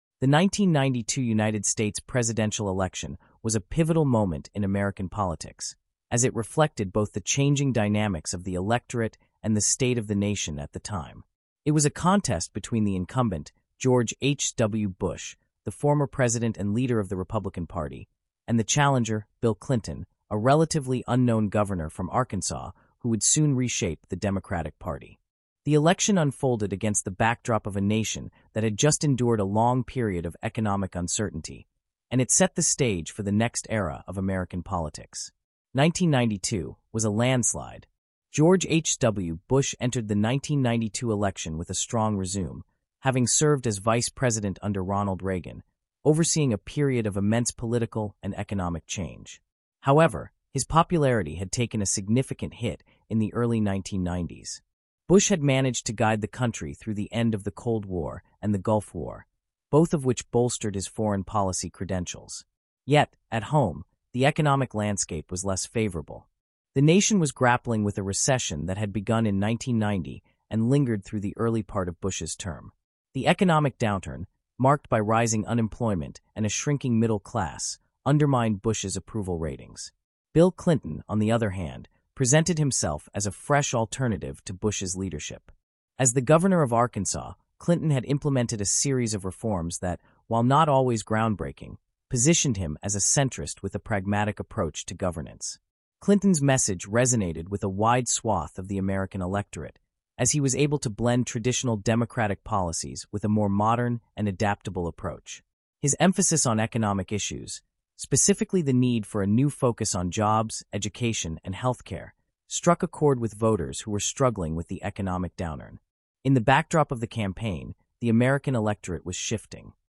Disclosure: This podcast includes content generated using an AI voice model.